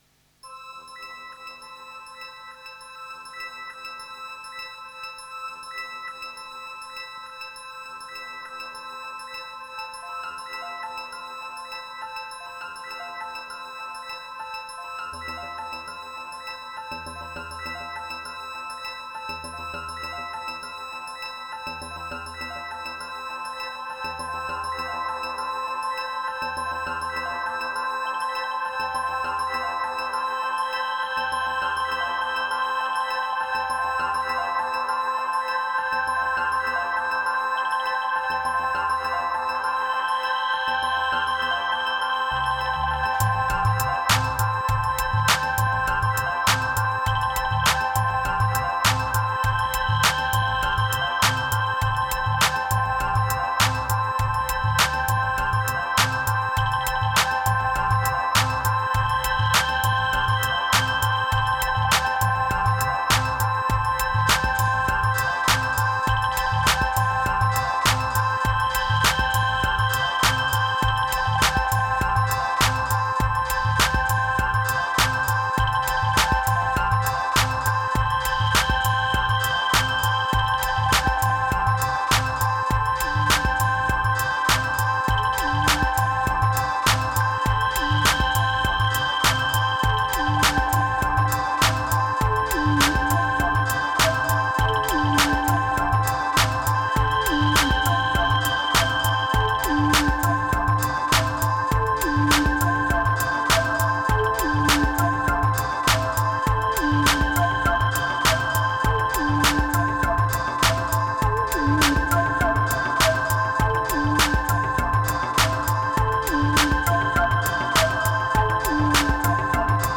253📈 - 99%🤔 - 101BPM🔊 - 2025-10-28📅 - 1341🌟
Just one tiny drum sample, the rest if composed/sequenced.